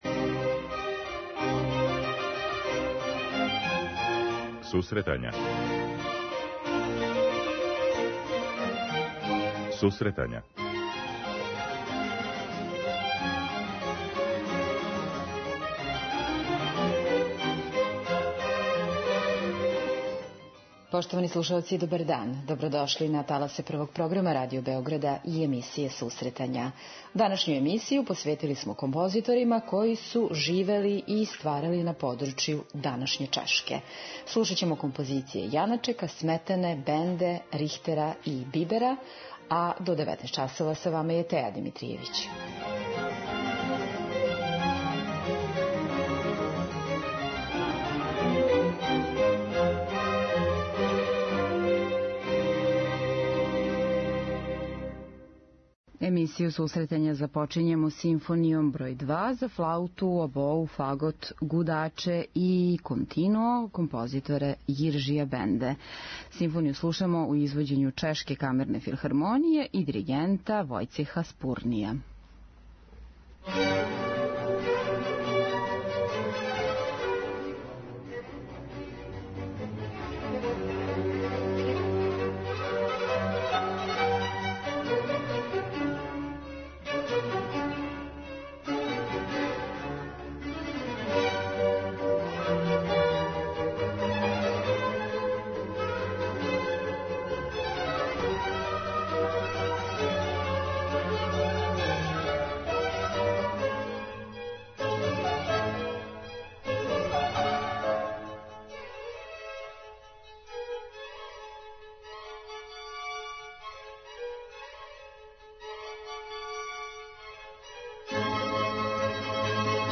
Музичка редакција Емисија за оне који воле уметничку музику.